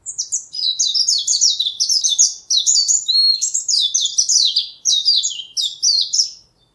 メジロさえずり
Jpn_white_eye1.mp3